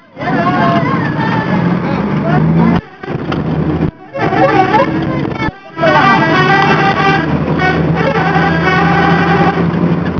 Each of these were originally recorded on my trusty Psion...
Bus horns in Rajastan, India (220K)
bus.wav